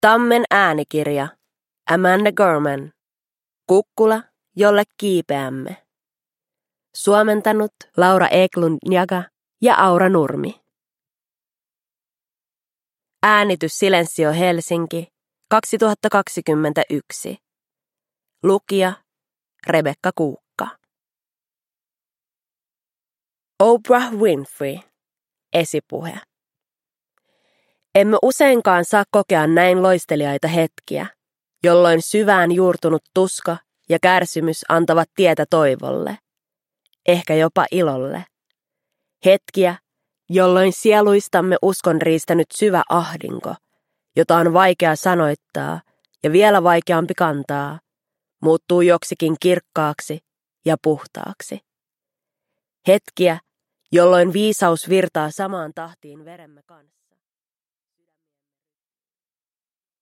Kukkula jolle kiipeämme – Ljudbok – Laddas ner